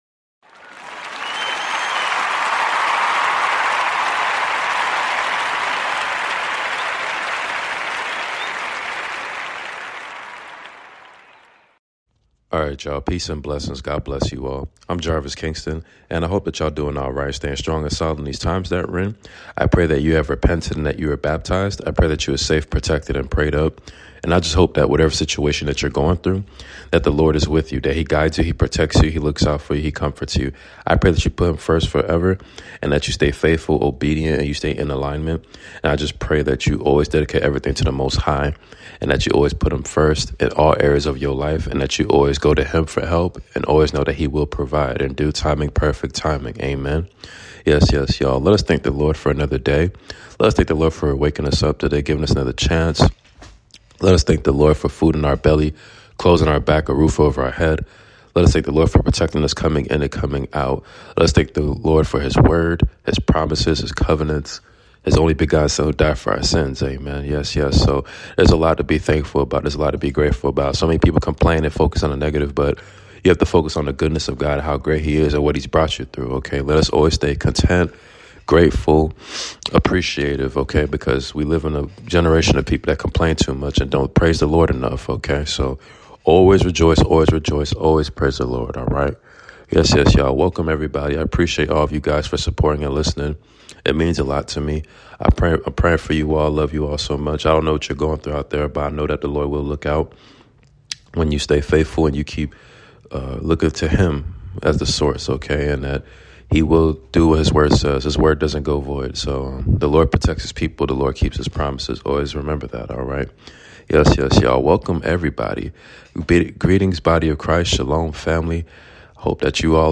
Book of 1 Timothy reading completion chapters 1-6 ! Amen